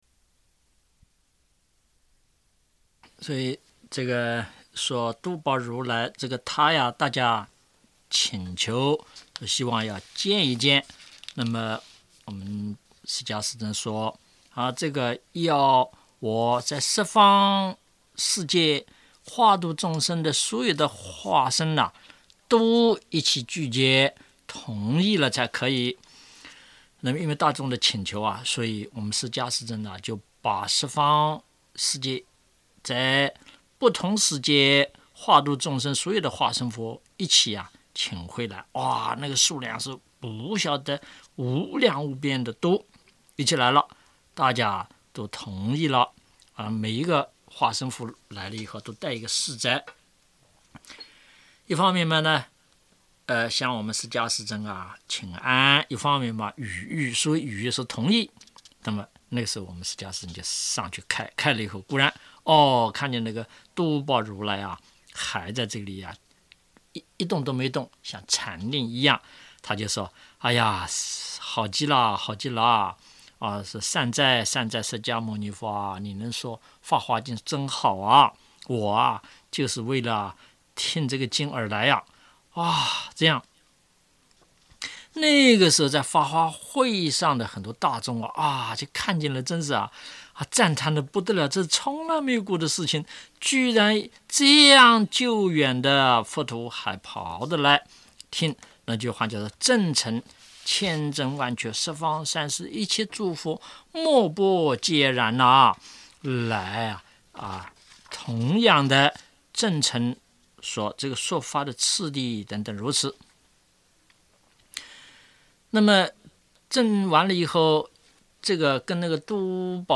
【Bingo任務6】聽師父講龍女成佛的故事